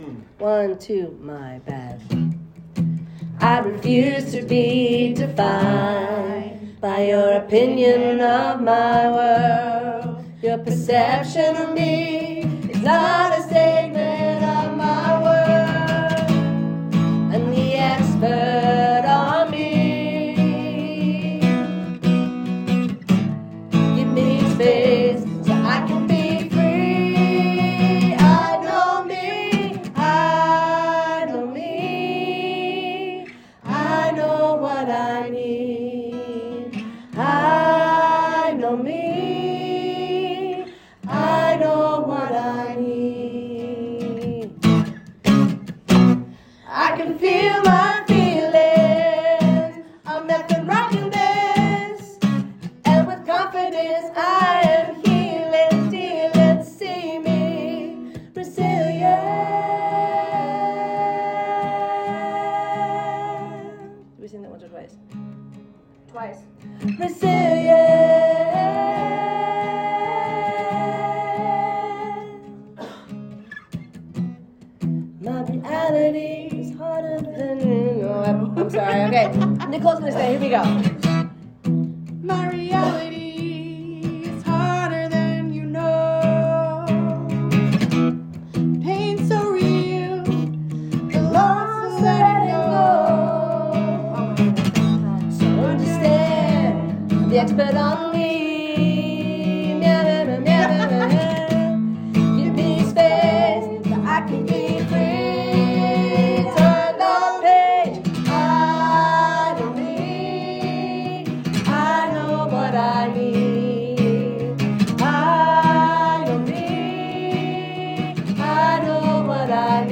I-Know-Me-AM-Community-Song-2023-SHARE-1.m4a